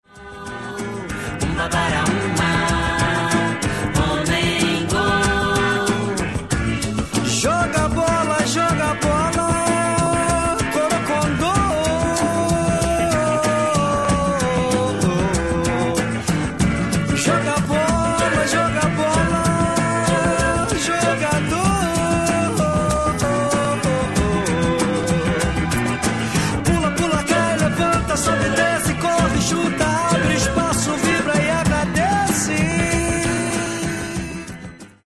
Genere:   Afro Brasil